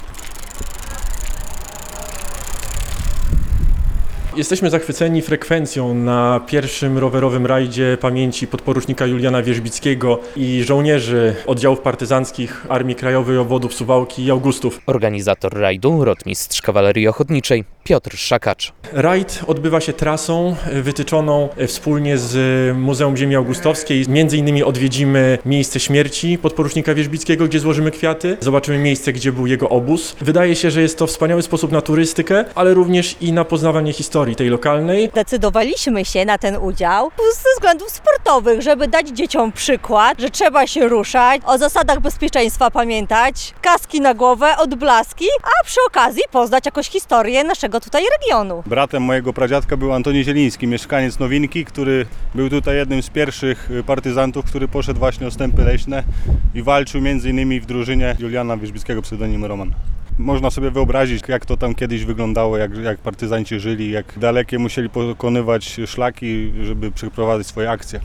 Rajd rowerowy w Nowince - relacja